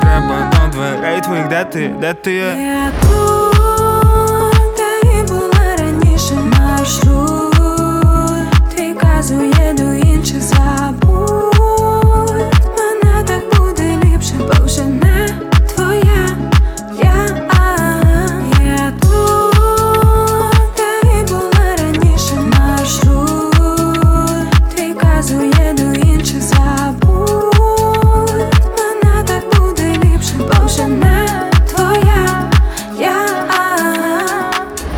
Pop Dance